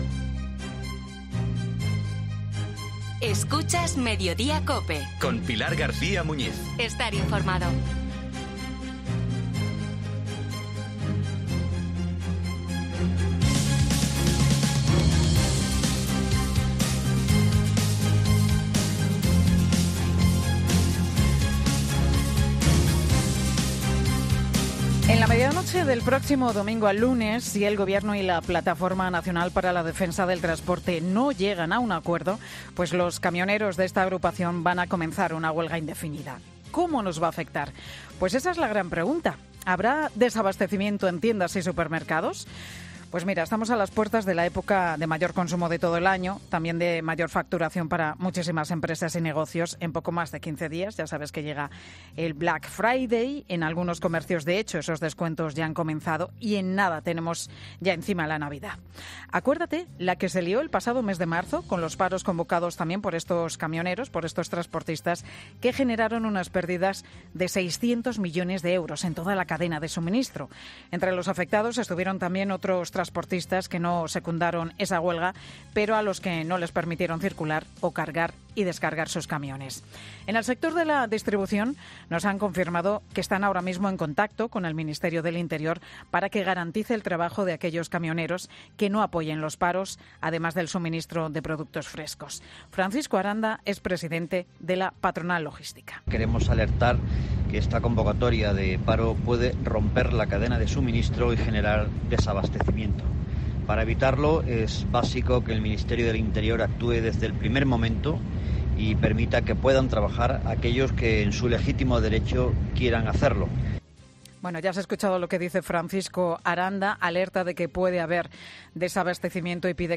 Mediodía COPE regresa ocho meses después al Mercado Delicias de Zaragoza para ver cómo afrontan los nuevos paros anunciados por parte del sector del transporte